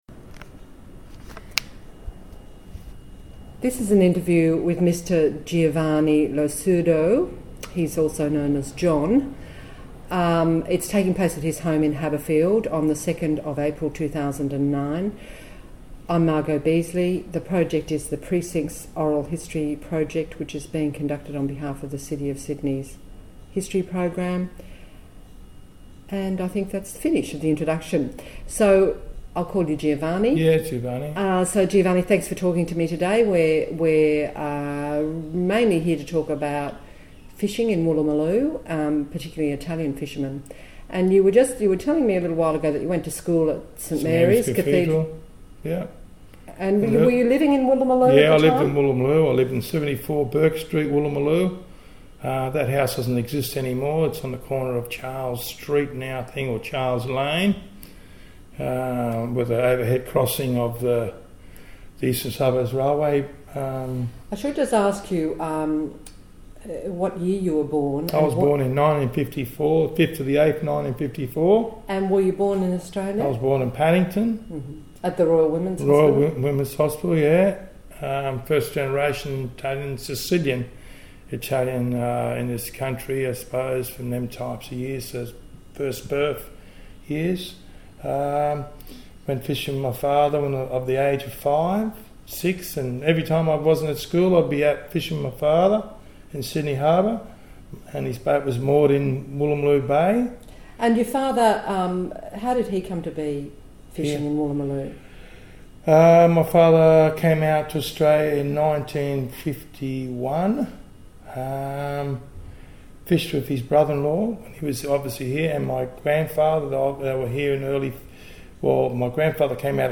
This interview is part of the City of Sydney's oral history theme: Our City